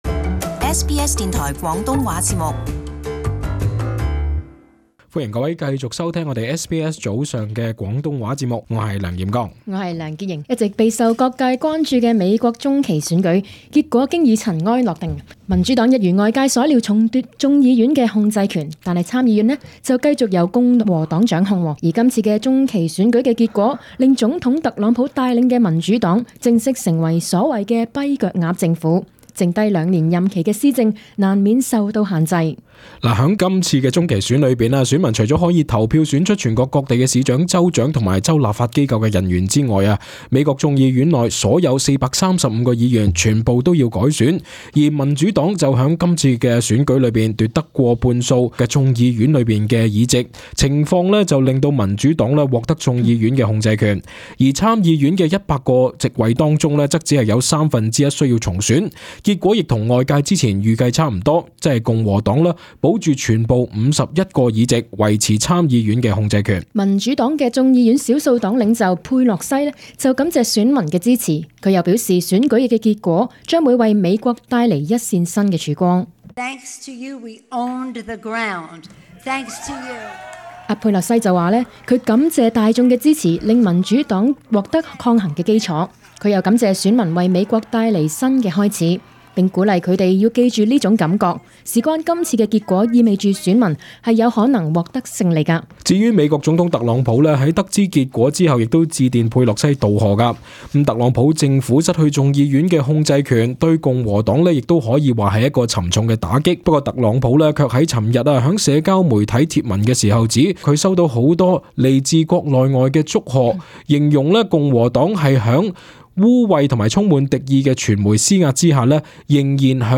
【時事報導】未能控眾院 特朗普未來施政將受制肘